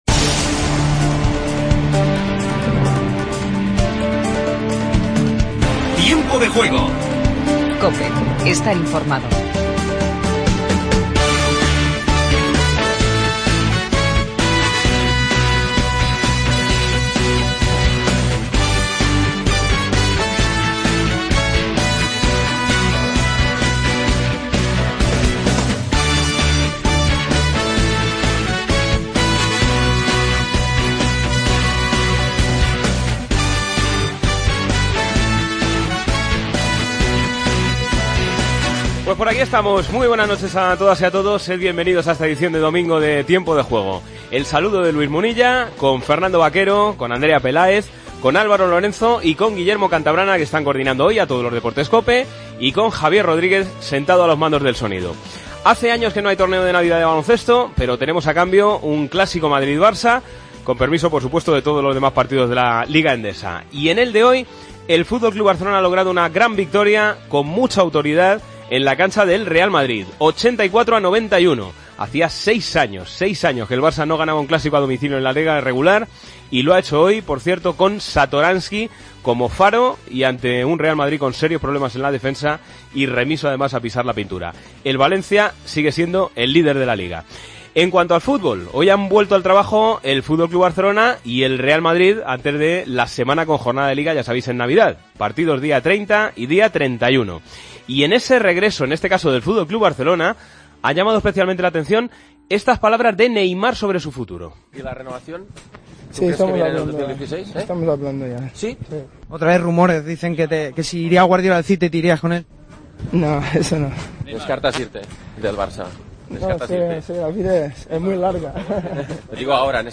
AUDIO: Titulares del día. Entrevista a Satoransky, base del Barça Lassa.